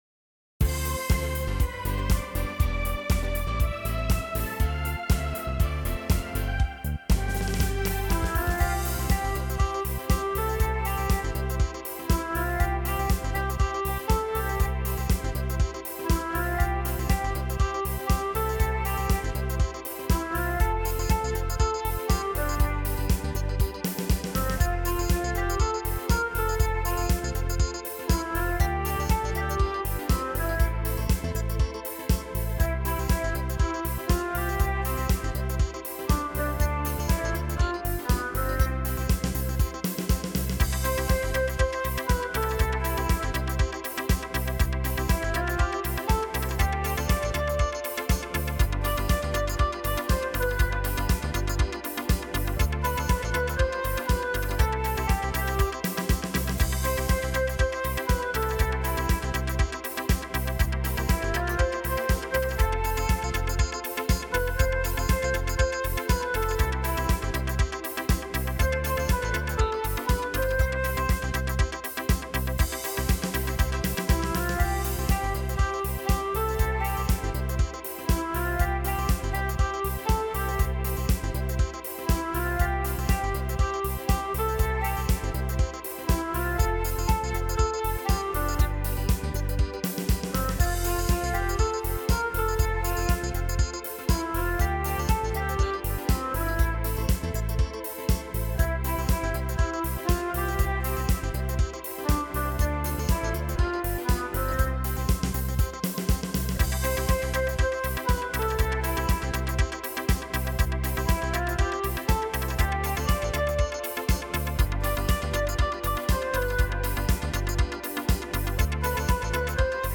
Disco-Fox